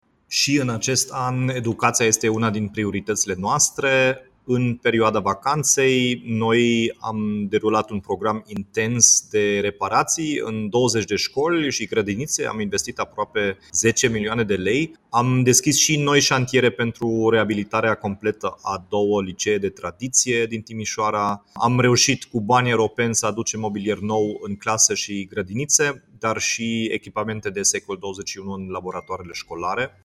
Dominic-Fritz-reparatii-scoli.mp3